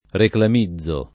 reclamizzare v.; reclamizzo [ reklam &zz o ]